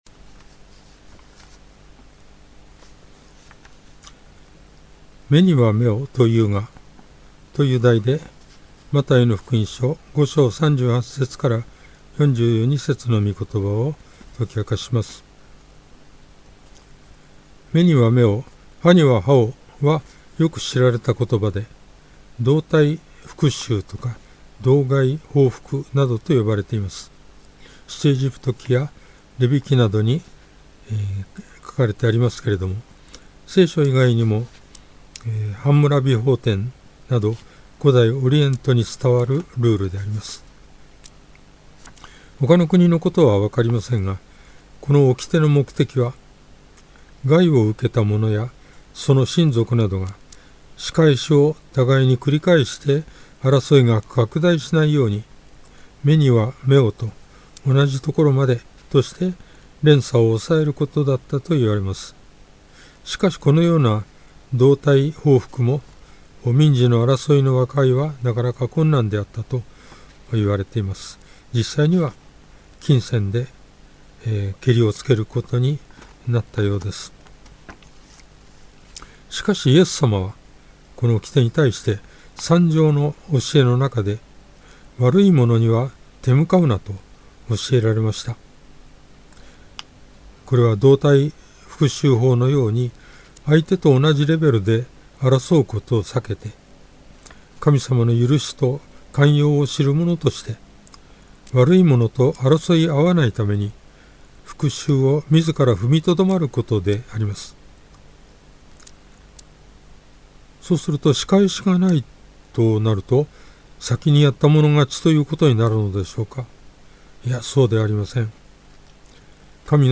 Sermon
もし自動的に音声が流れない 場合、ここをクリック 当日の説教 <準備中> 事前録音分 説教要旨 「目には目を、歯には歯を」とは、被害者が加害者に 同じ程度の害を加えて復讐することを認める規定で、 同害復讐法、などと呼ばれている。